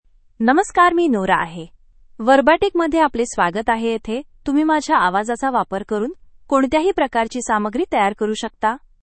Nora — Female Marathi AI voice
Nora is a female AI voice for Marathi (India).
Voice sample
Listen to Nora's female Marathi voice.
Female